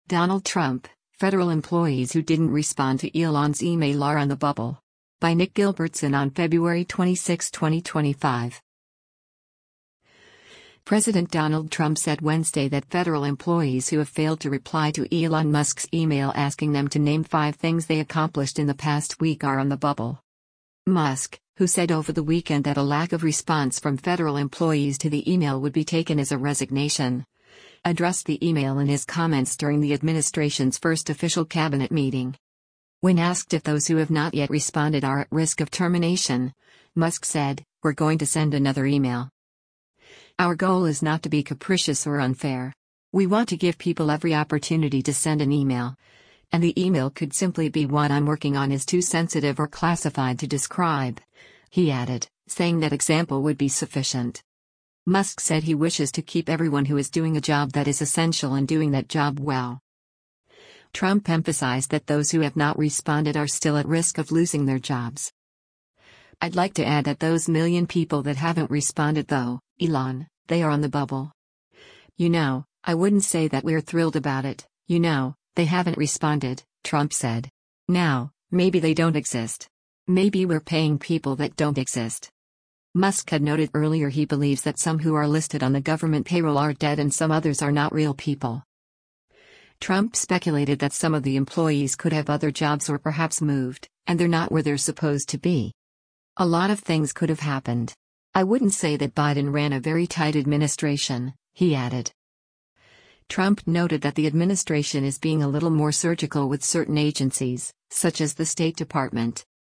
Musk, who said over the weekend that a lack of response from federal employees to the email would be taken as a resignation, addressed the email and his comments during the administration’s first official cabinet meeting.